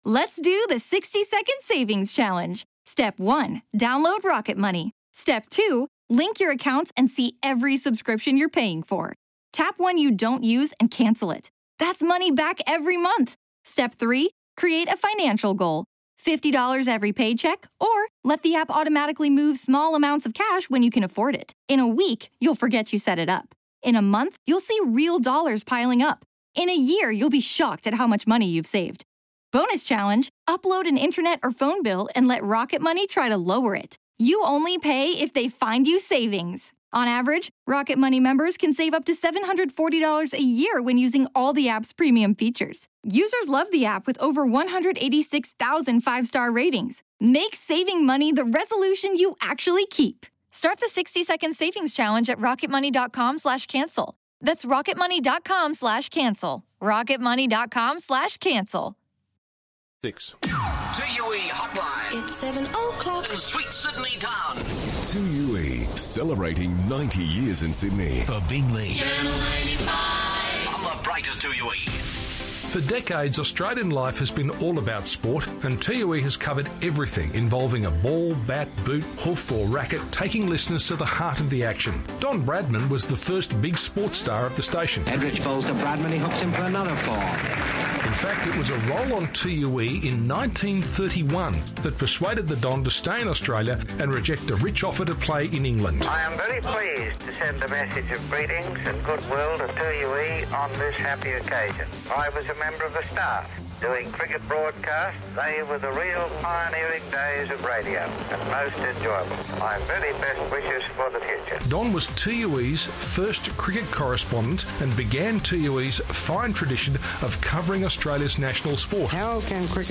Take a listen back to some classic 2UE flashbacks on 90 years of broadcasting to Sydney and around the globe.